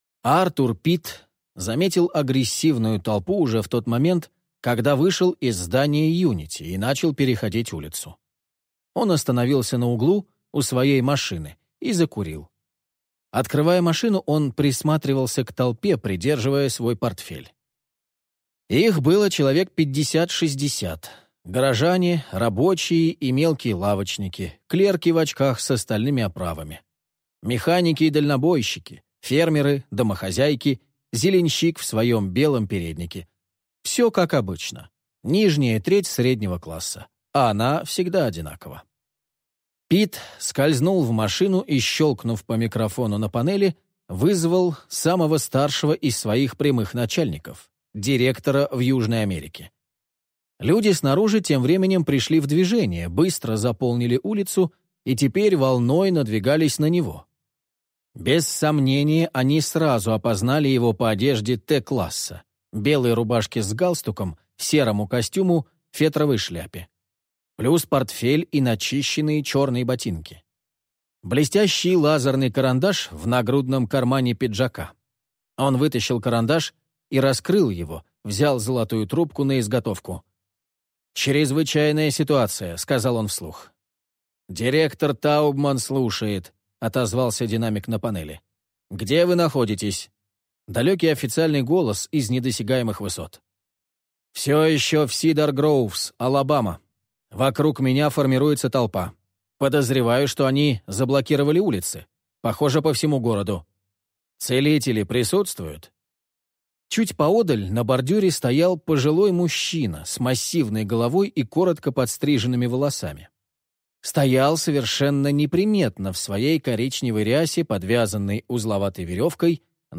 Аудиокнига Молот Вулкана | Библиотека аудиокниг